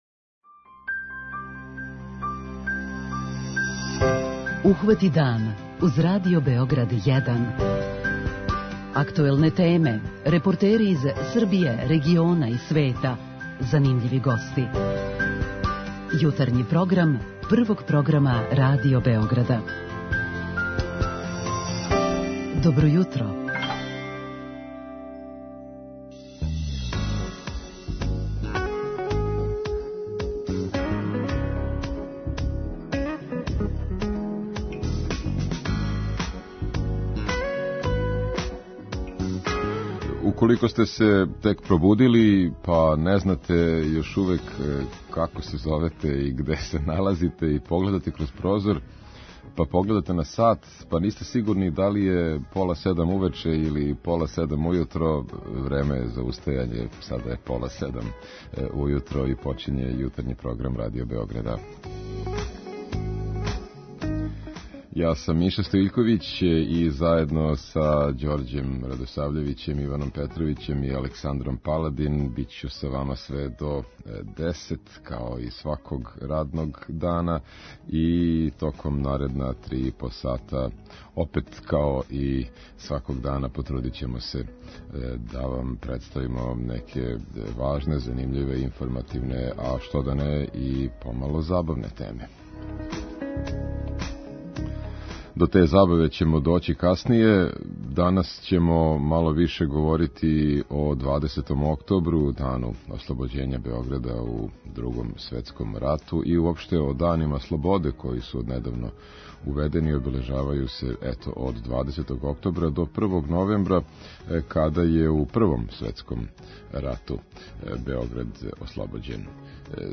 У емисију ће нам се укључити и дописник из Лесковца који је од јуче без воде због великог квара на магистралном водоводу.